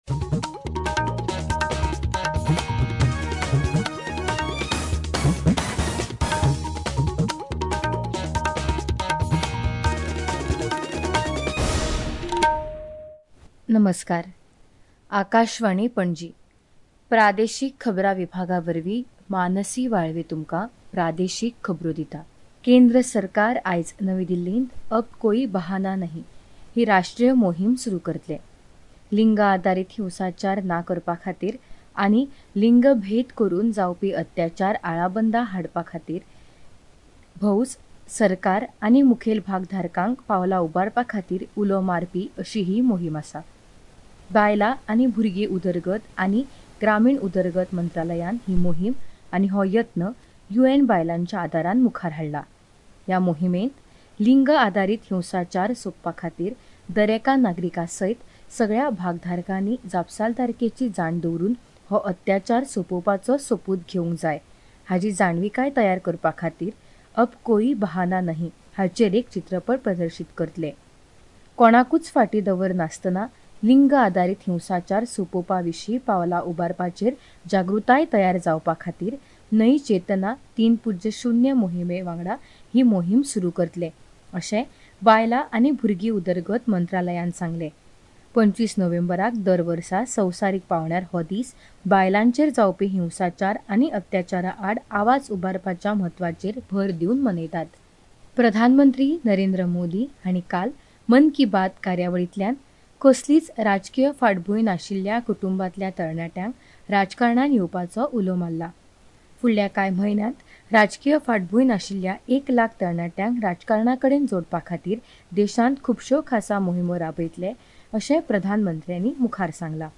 Access Bulletins From Cities